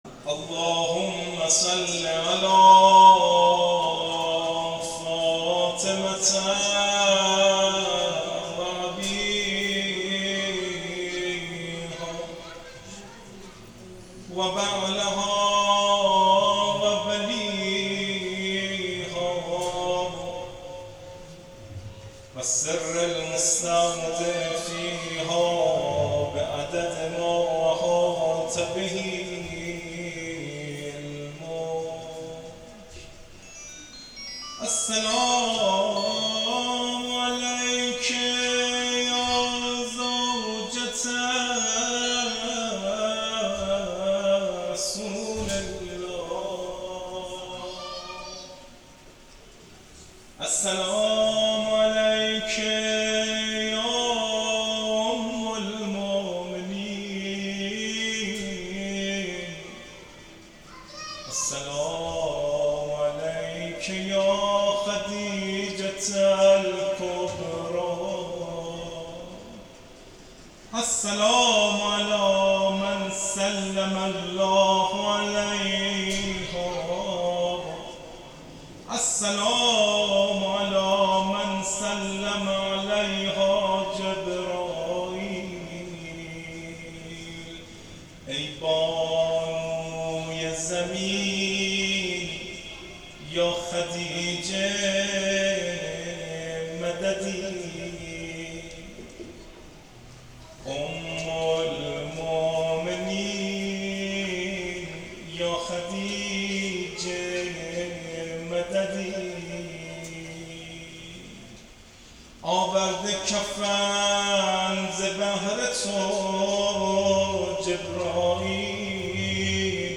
روضه حضرت خدیجه سلام الله علیها